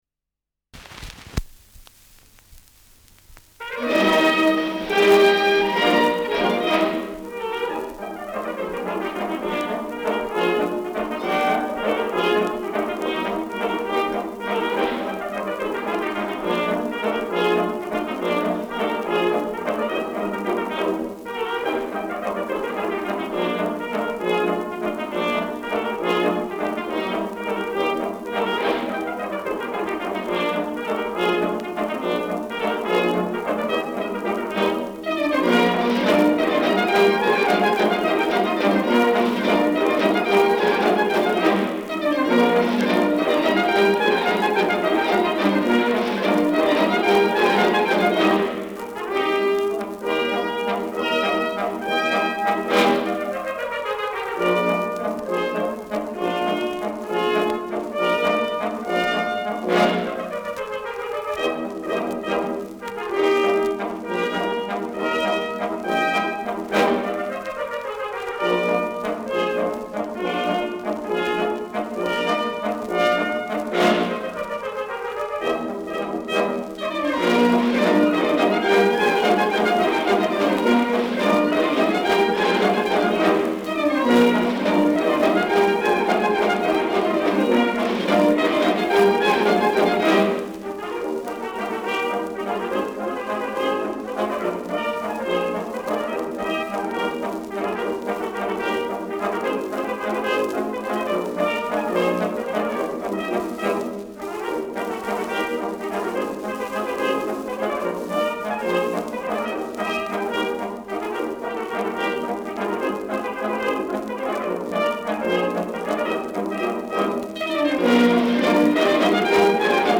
Schellackplatte
Tonrille: Kratzer 5 / 7 / 11 Uhr Leicht
Starkes Grundknistern : erhöhter Klirrfaktor
Fränkische Bauernkapelle (Interpretation)